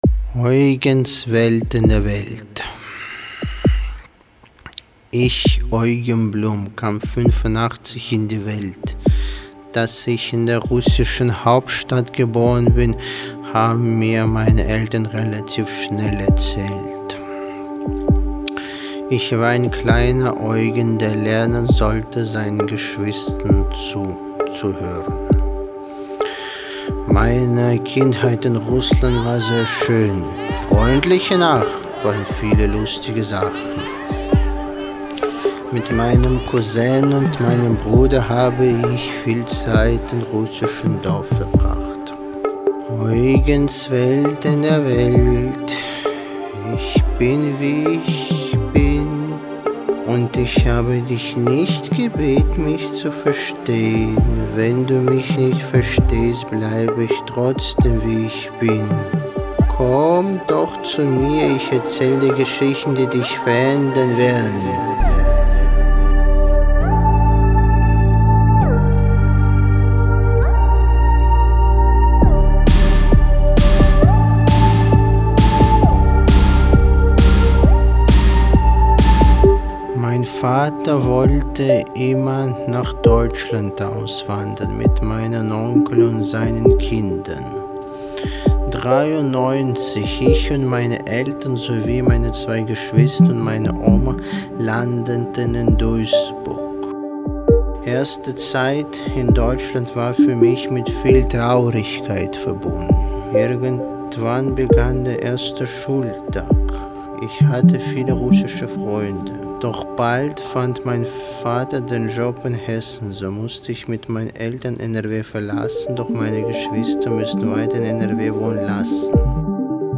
Lied „Eugens Welt in der Welt“